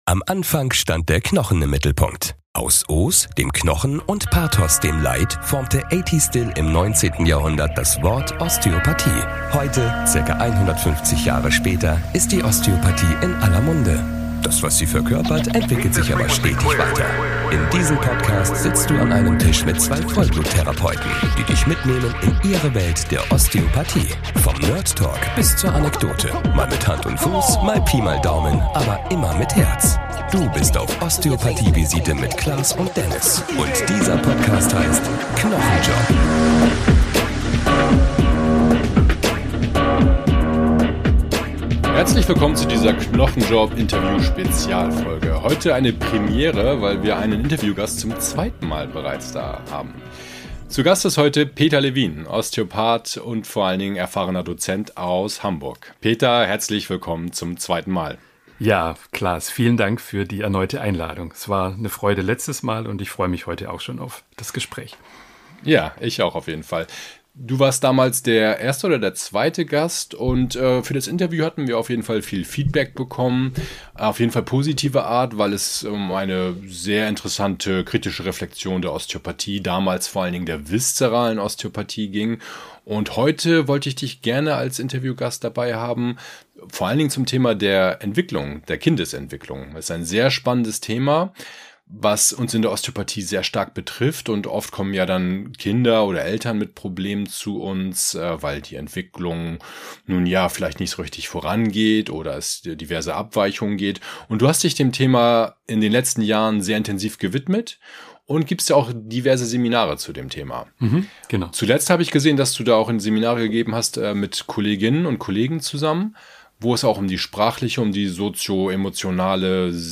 Interview-Spezial